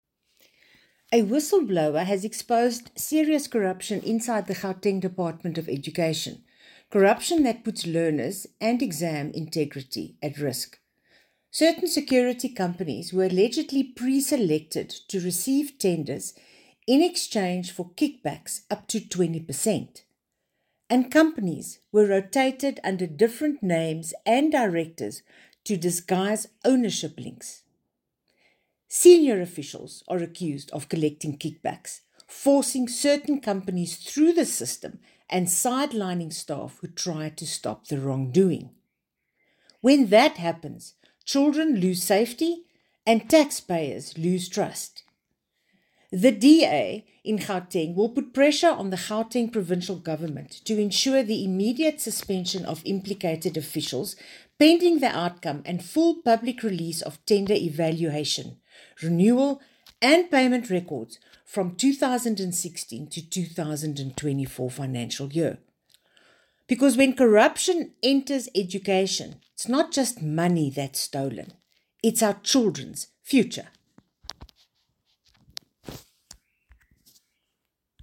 Afrikaans soundbites by Bronwynn Englebrecht MPL.